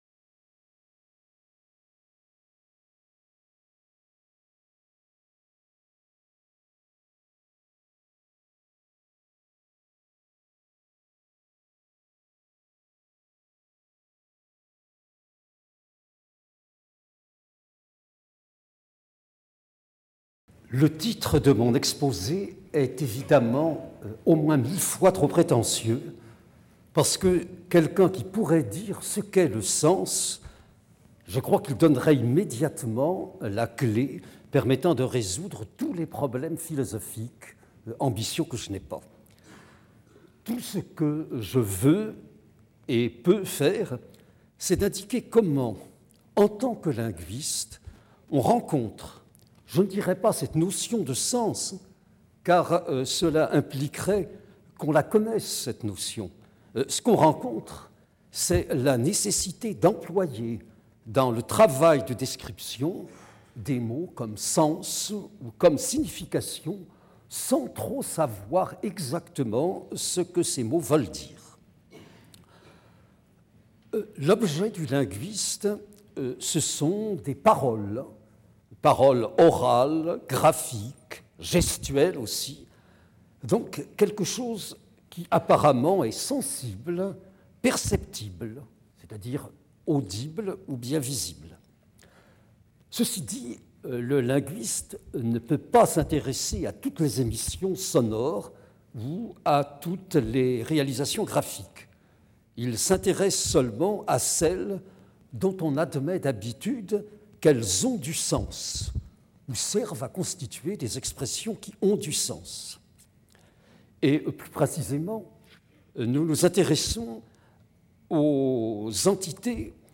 Conférence du 17 février 2000 par Oswald Ducrot.